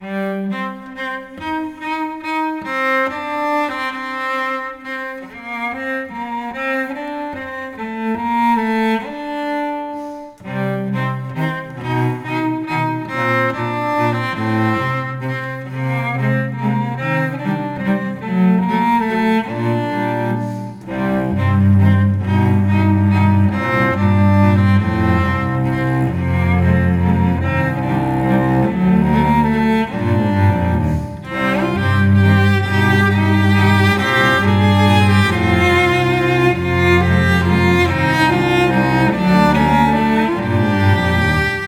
arranged for cello & looper